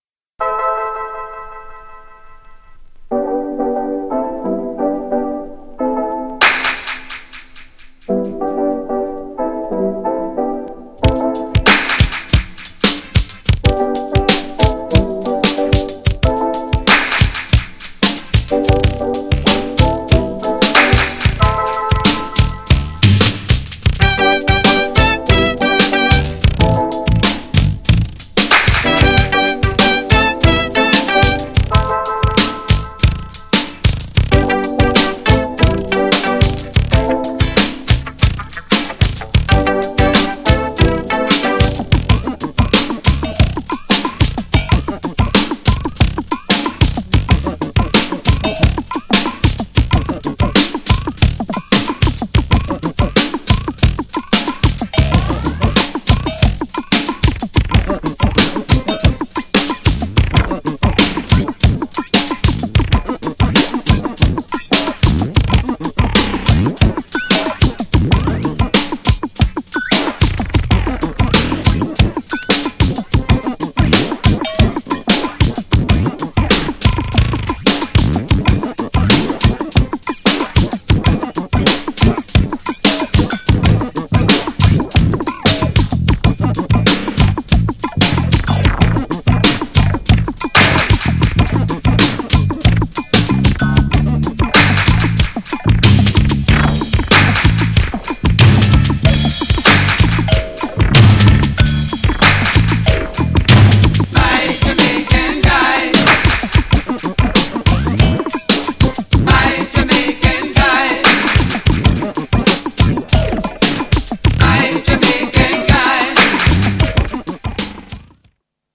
GENRE Dance Classic
BPM 91〜95BPM
# DEEP_SPACE # GARAGE_CLASSIC
# ダビー
# レゲエ # 空間的